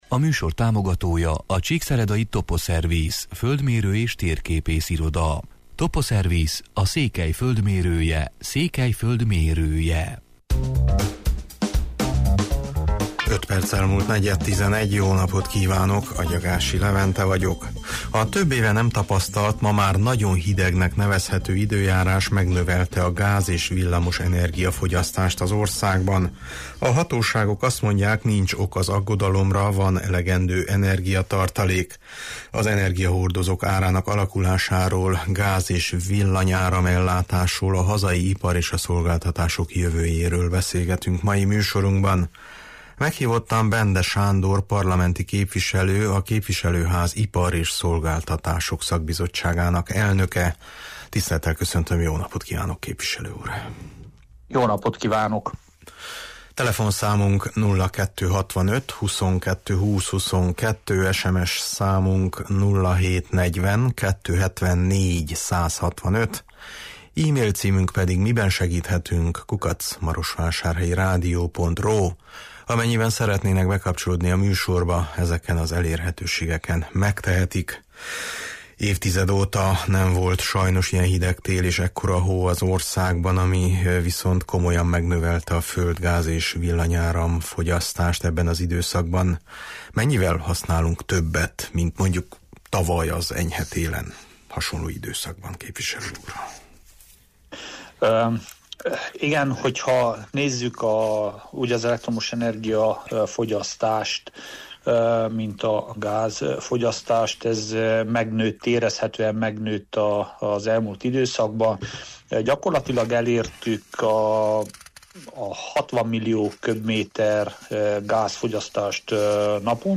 Meghívottam Bende Sándor parlamenti képviselő, a Képviselőház Ipar és szolgáltatások szakbizottságának elnöke.